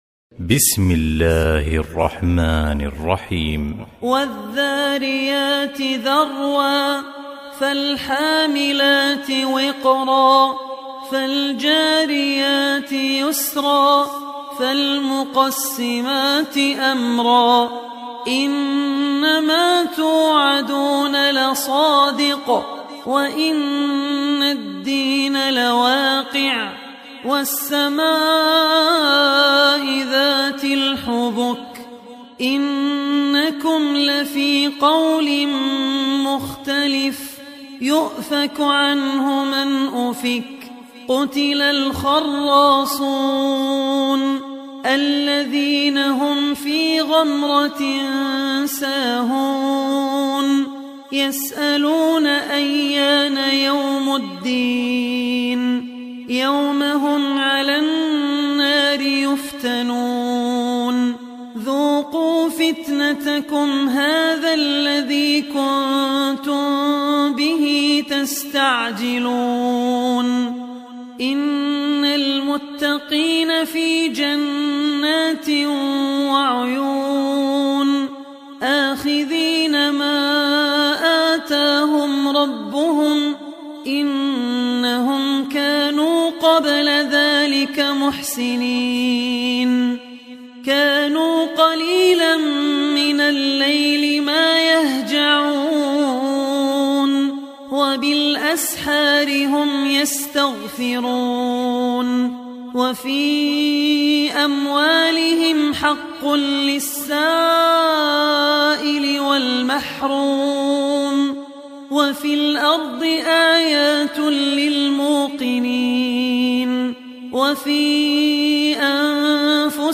Surah Ad Dhariyat Beautiful Recitation MP3 Download By Abdul Rahman Al Ossi in best audio quality.